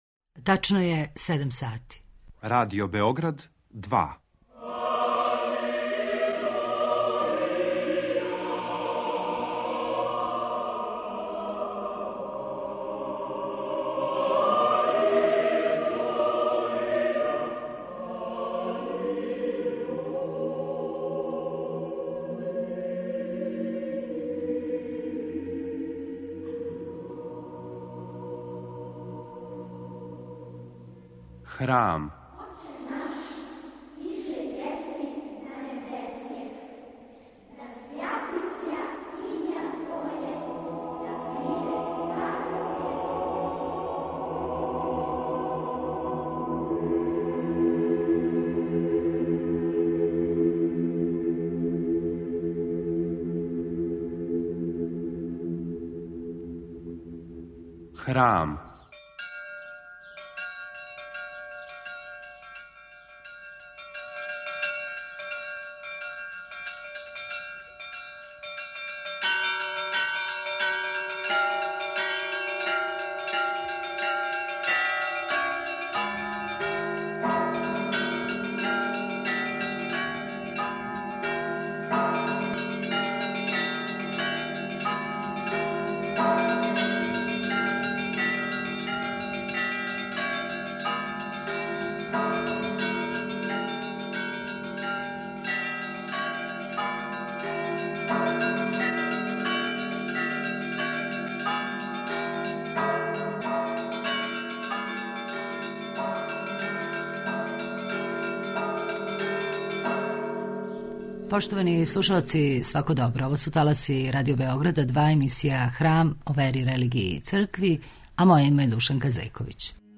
Говори проф. др Дарко Танасковић.